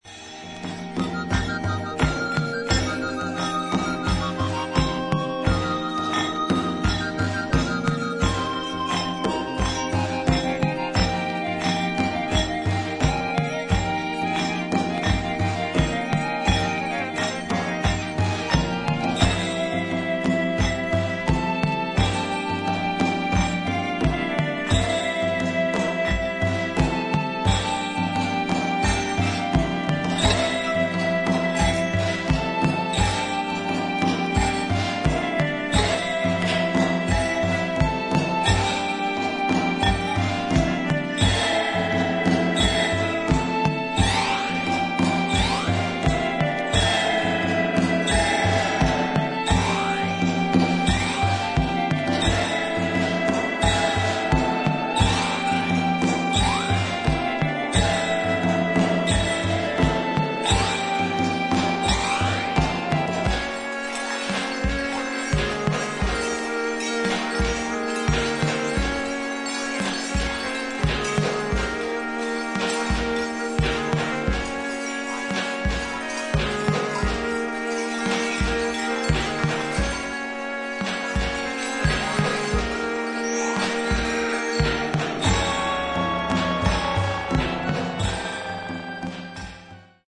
80'sエレクトロニックな感覚とシネマティックな雰囲気が随所に感じられるコンセプチュアルなコンピレーション作品